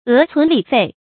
發音讀音
é cún lǐ fèi